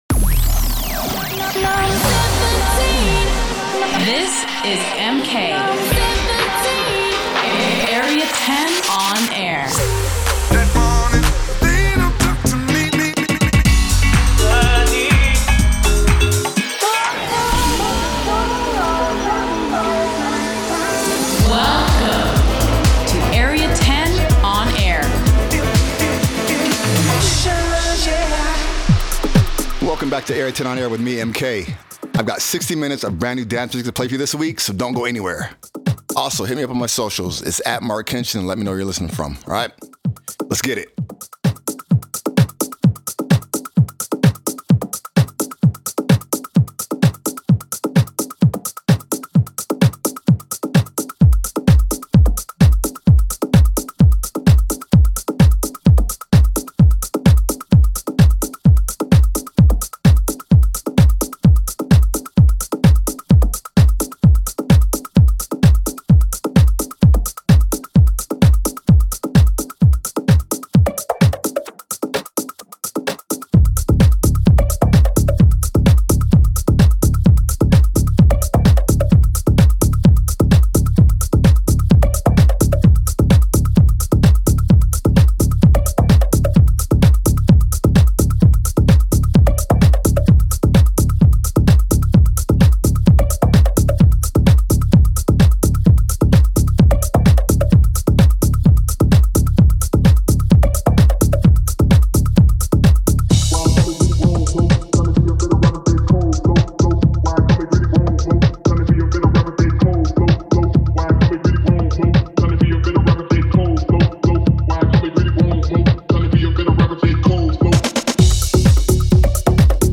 Delving in to house and techno